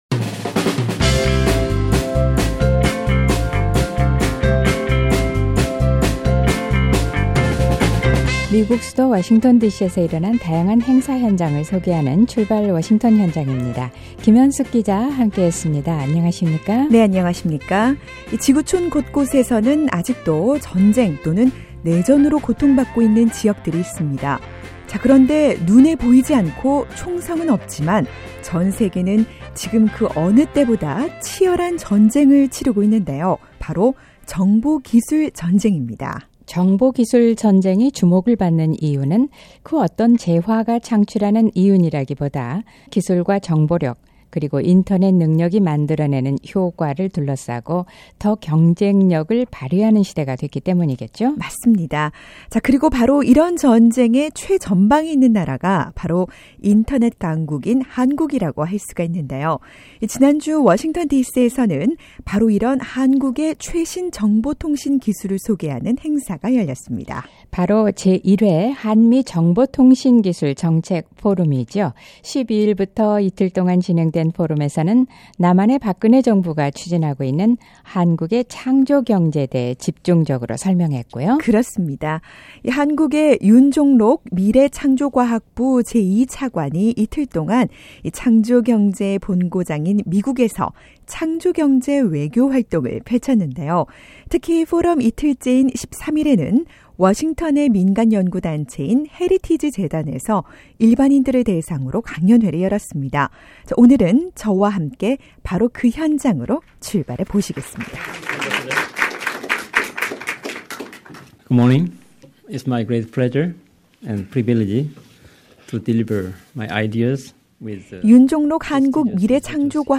민간연구단체 헤리티지 재단에서 열렸던 ‘한국의 창조경제 강연회’ 현장으로 출발해봅니다.